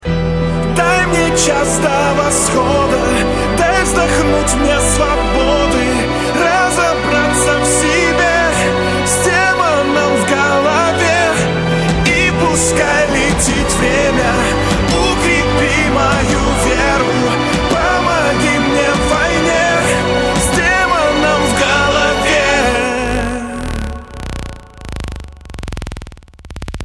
Припев